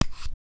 Chill UI Sound.wav